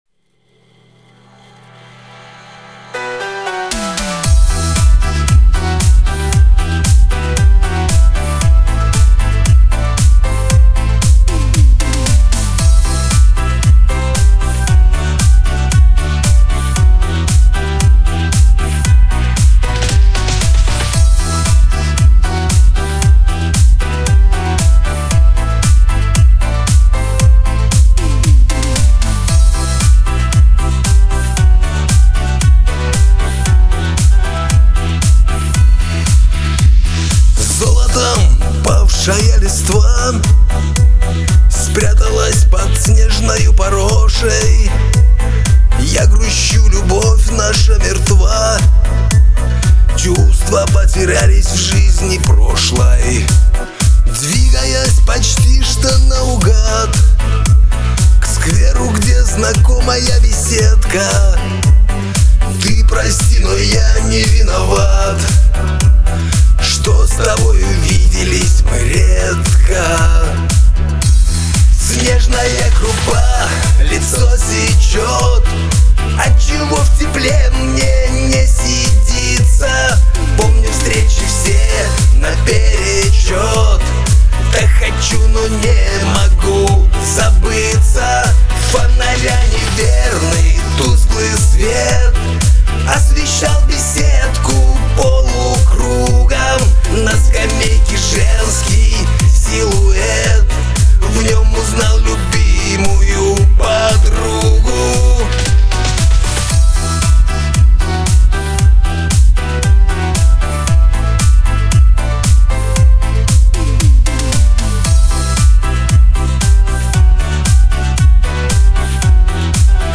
Шансон__Встреча_2021_